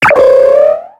Cri de Karaclée dans Pokémon X et Y.